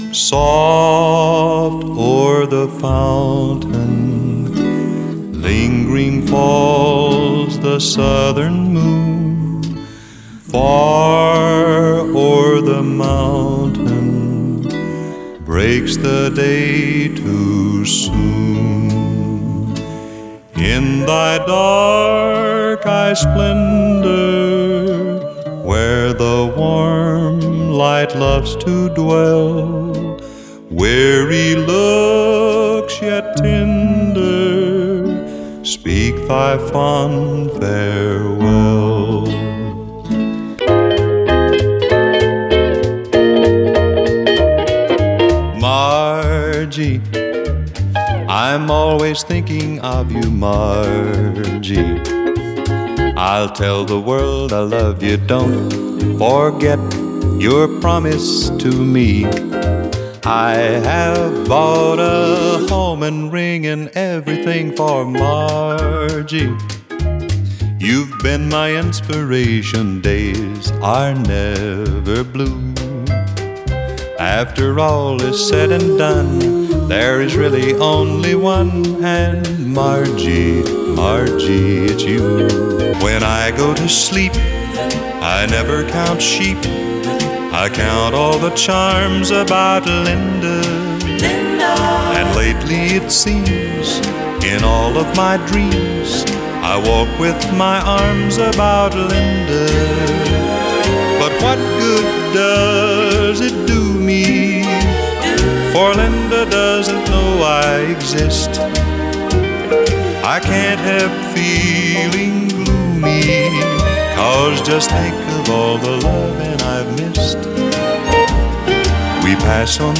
pop-flavored songs
(Be sure to use a device that can reproduce stereo!)